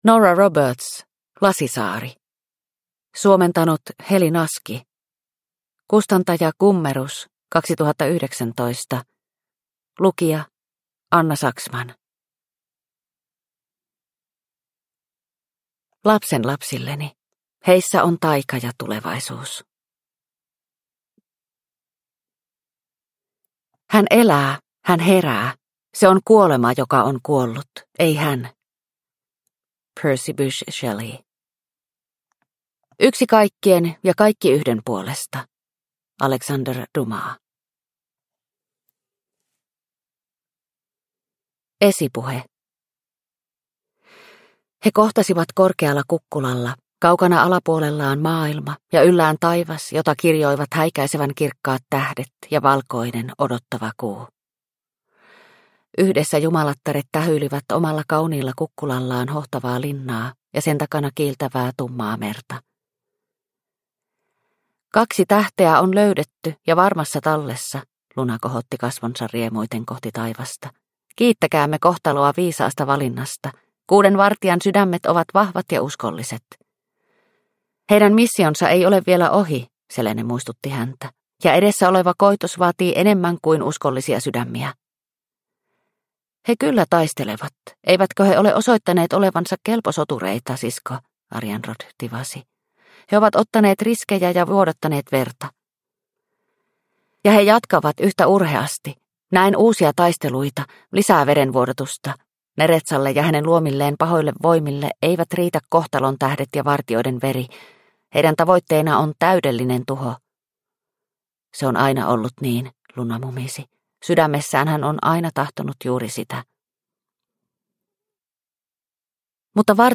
Lasisaari – Ljudbok – Laddas ner